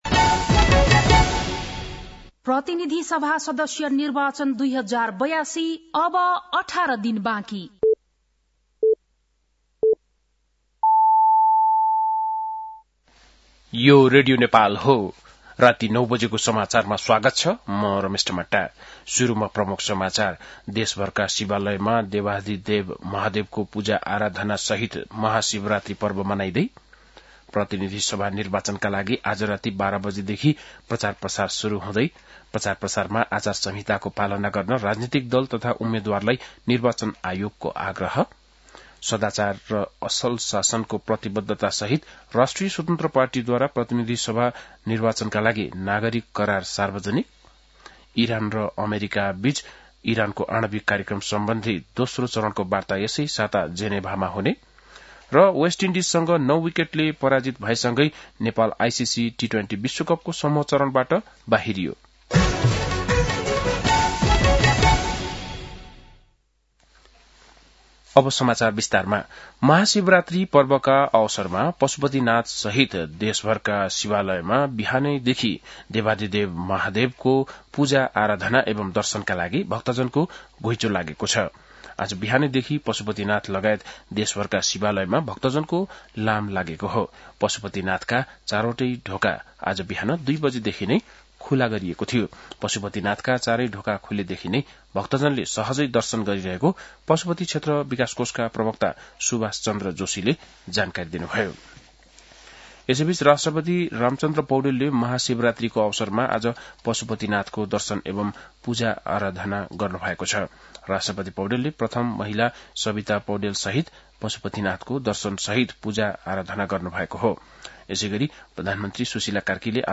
बेलुकी ९ बजेको नेपाली समाचार : ३ फागुन , २०८२
9-pm-nepali-news-11-03.mp3